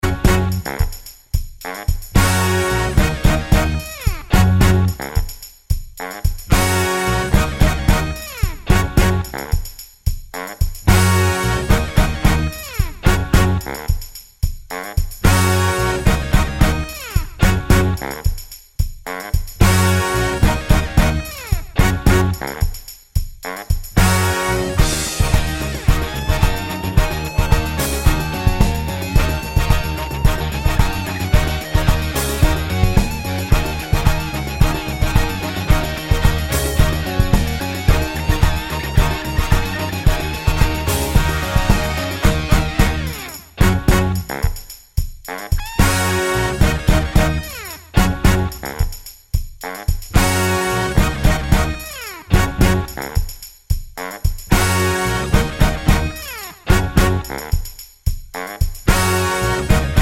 Soul / Motown 2:22 Buy £1.50